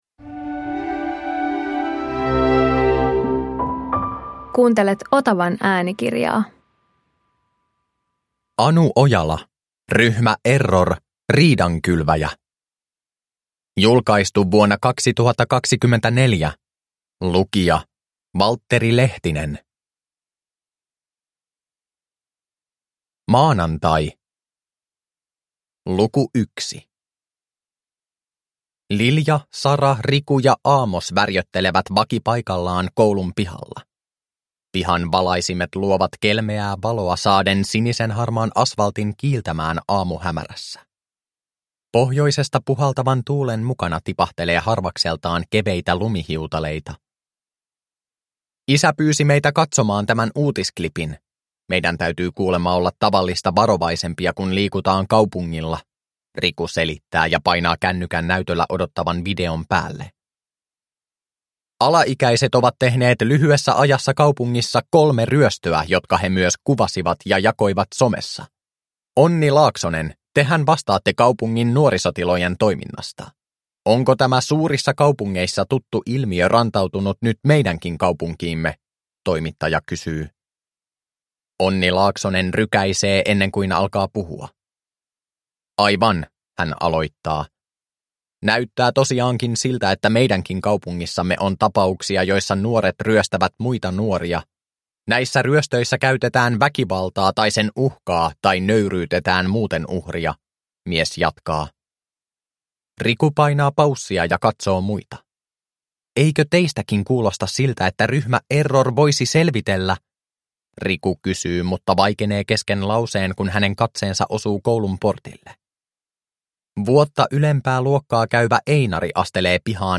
Ryhmä Error - Riidankylväjä – Ljudbok